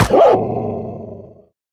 Minecraft Version Minecraft Version latest Latest Release | Latest Snapshot latest / assets / minecraft / sounds / mob / wolf / angry / hurt1.ogg Compare With Compare With Latest Release | Latest Snapshot
hurt1.ogg